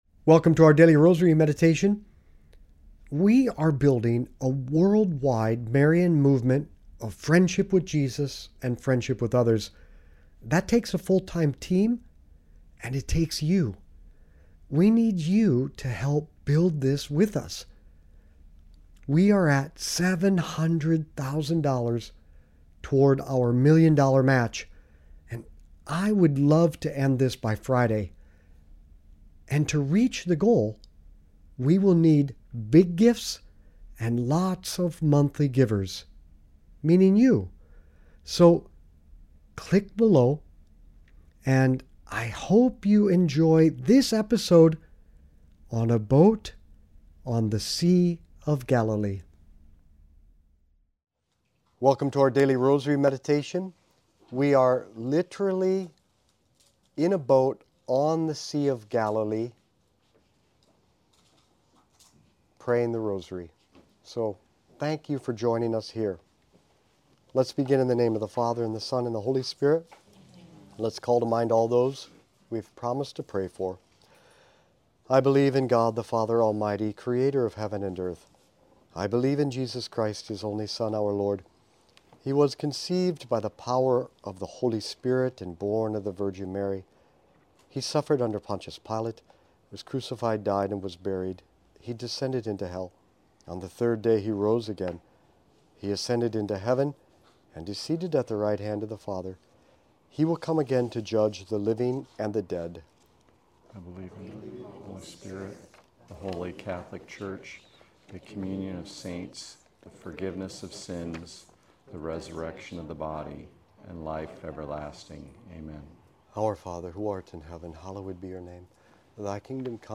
Daily Rosary Meditations
We are literally in a boat on the sea of Galilee,